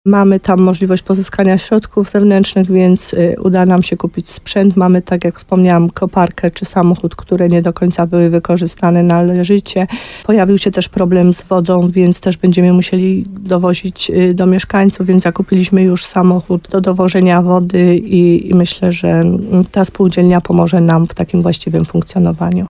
Jak mówi wójt Małgorzata Gromala, spółdzielnia pozwala na współpracę z lokalnymi przedsiębiorcami, ale też na świadczenie różnego rodzaju usług.